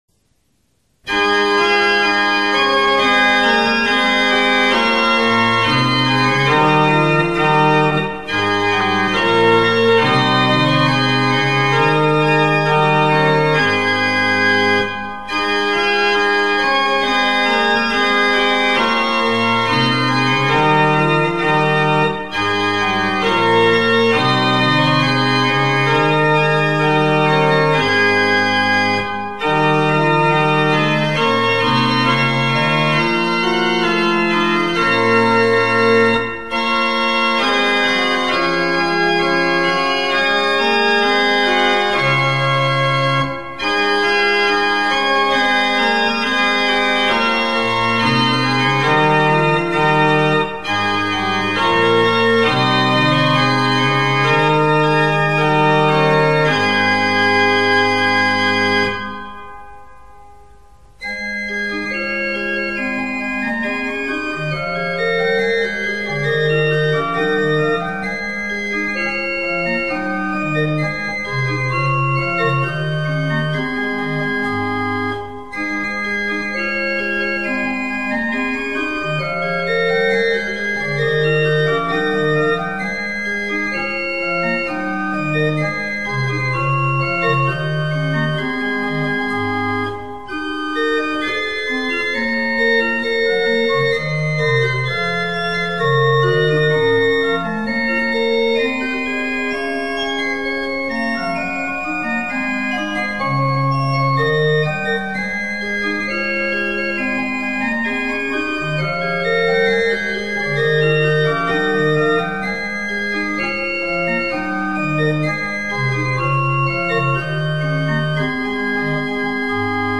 Orgue
0904_Partita_Were_munter_mein_Gemute_Johann_Pachelbel_Orgue.mp3